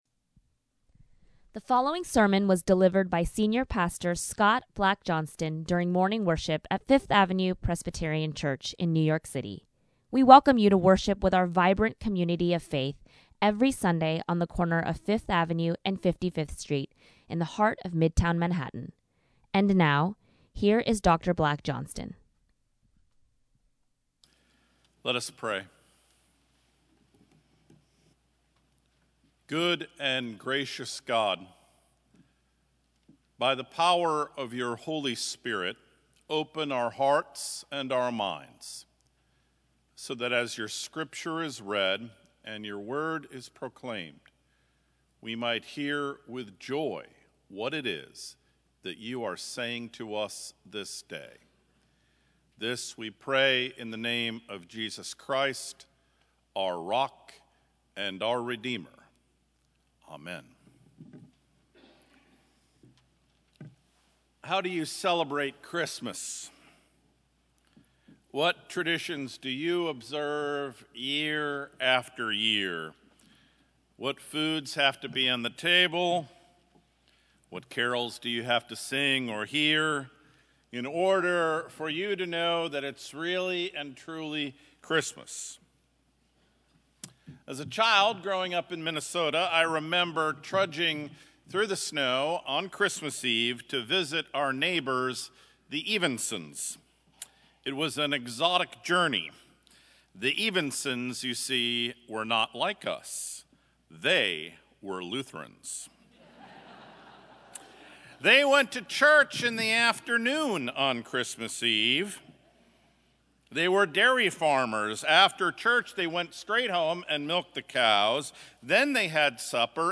FAPC Sermon Series: Christmas at God’s House - Advent 2018
Christmas Eve Candlelight Service